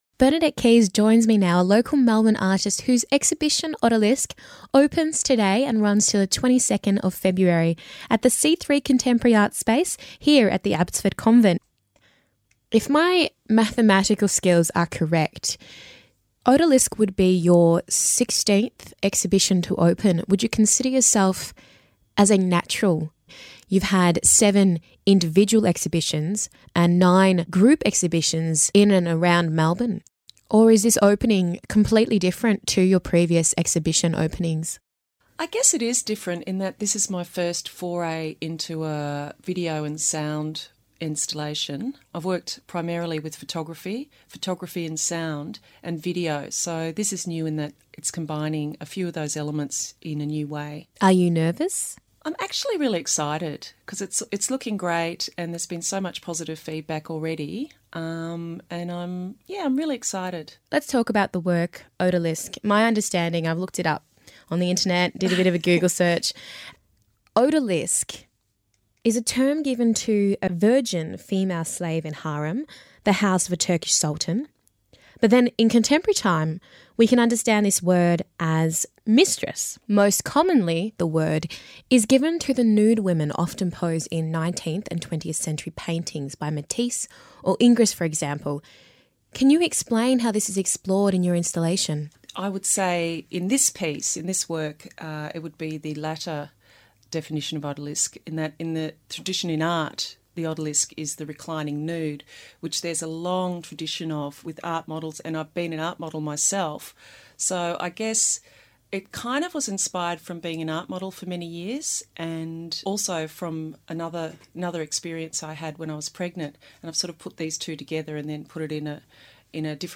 Odalisque interview
in conversation about Odalisque on 3mbs radio Melbourne